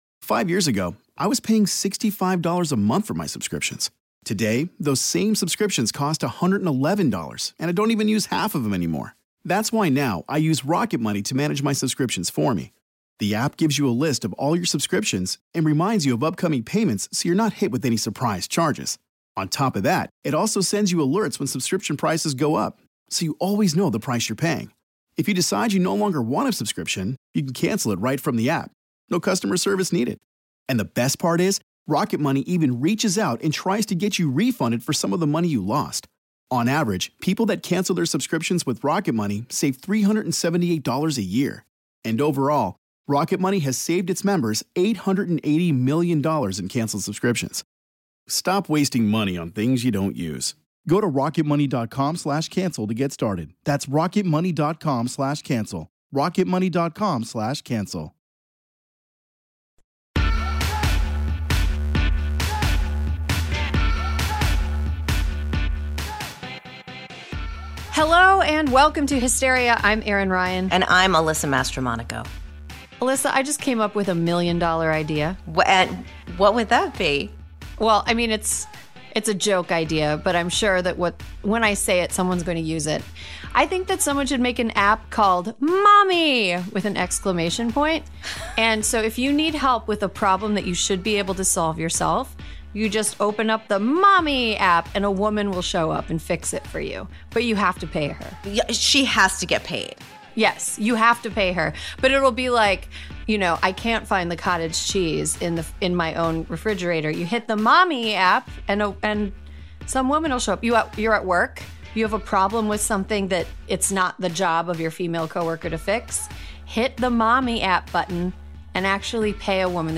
Erin Ryan and Alyssa Mastromonaco break down the new Texas abortion ban, SCOTUS's deafening silence, and what this means for Roe v. Wade. Then in a special labor day episode, the panel gets real about reality TV.